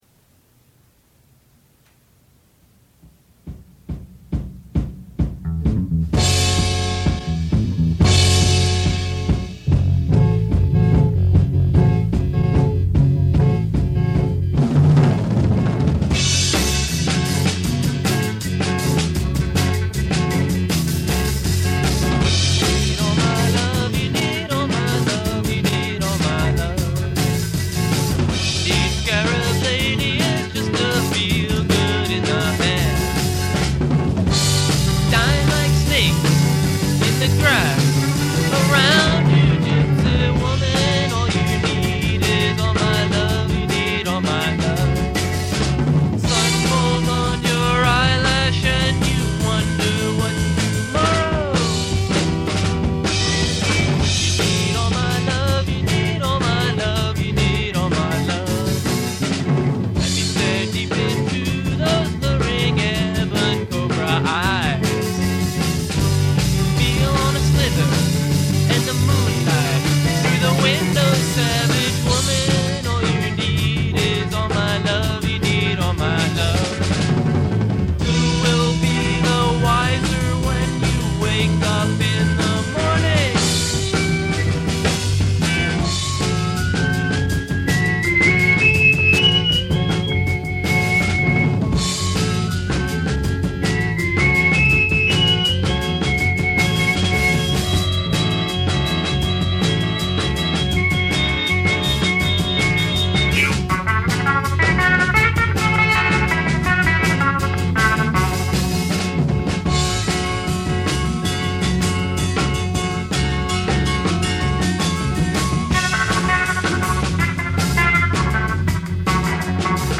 percussion, vocals
bass guitar
keyboards, vocals
guitar, vocals